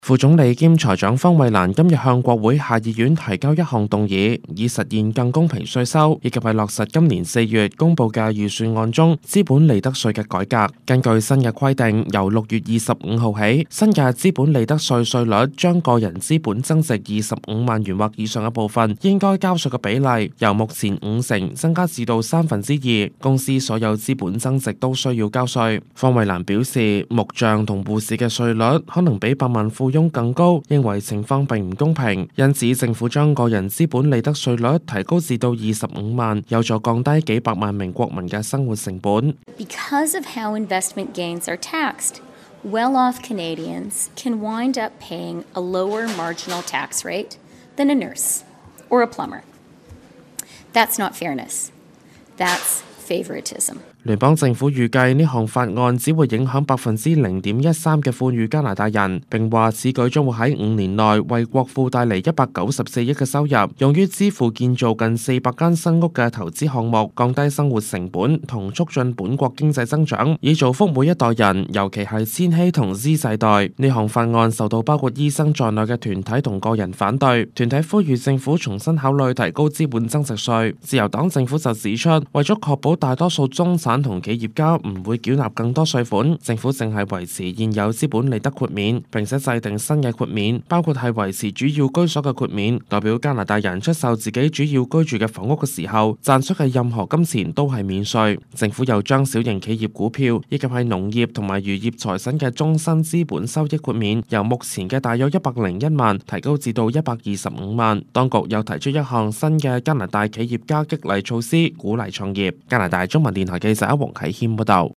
news_clip_19263.mp3